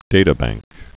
(dātə-băngk, dătə-)